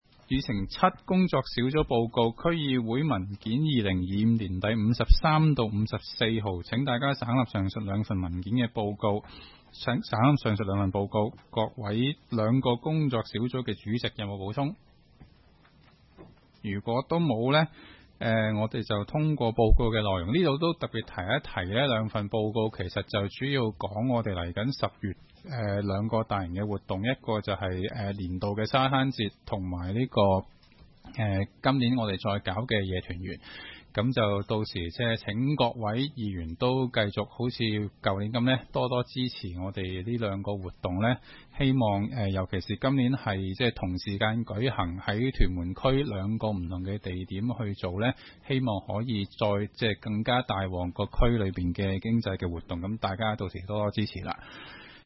区议会大会的录音记录
屯门区议会会议室